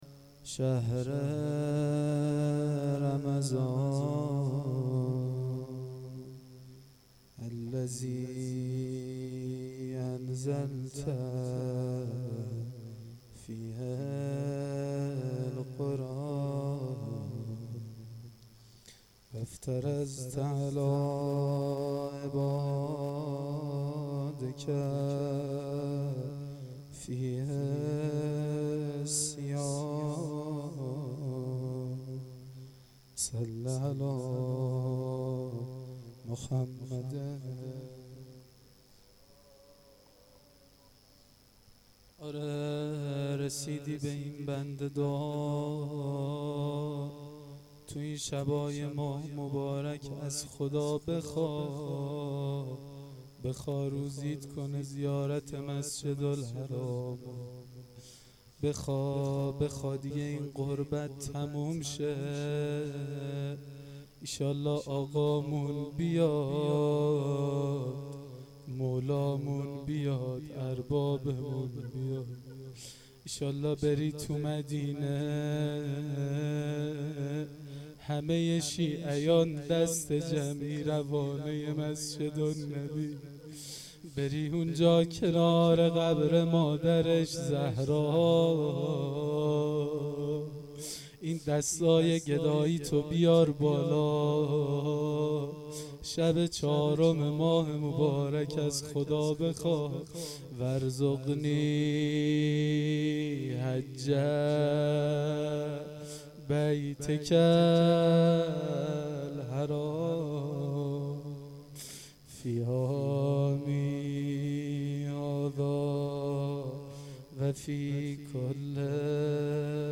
مناجات وروضه شب 4